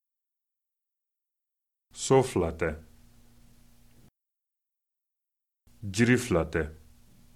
Dans une séquence de trois tons HAUT BAS HAUT, le deuxième ton HAUT ne sera pas aussi haut que le premier.